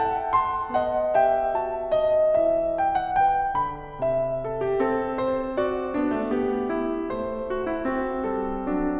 For my dataset, I chose two piano composers of different style, J.S. Bach and F. Schubert. Here is a 10-sec piece by Bach: